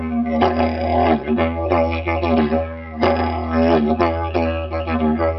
迪吉里杜管 6
用AudioTechnica Pro 70迷你话筒录制。 17年的巨型木制竹迪吉里杜管（由我制作）。
Tag: 90 bpm Ethnic Loops Didgeridoo Loops 931.17 KB wav Key : Unknown